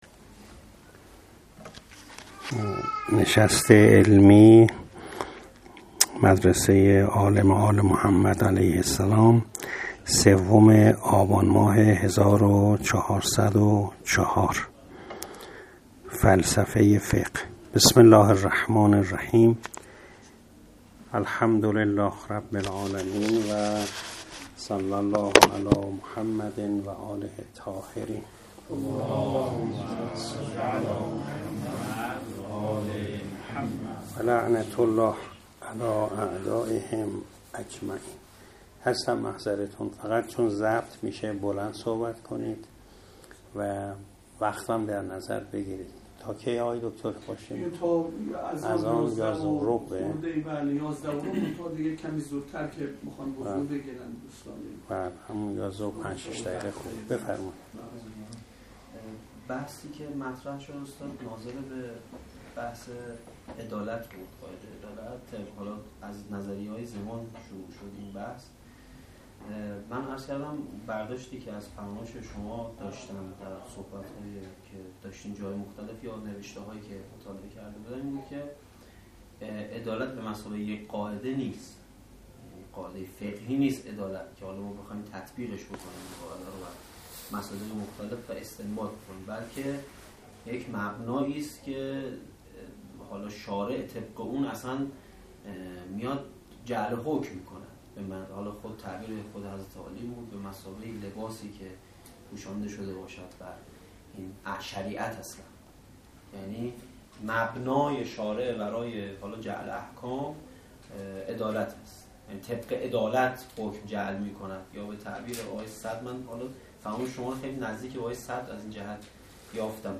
جلسه پرسش و پاسخ با طلاب مدرسه عالم آل محمد